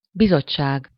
Ääntäminen
Ääntäminen France: IPA: /kɔ.mi.te/ Haettu sana löytyi näillä lähdekielillä: ranska Käännös Ääninäyte 1. bizottság Suku: m .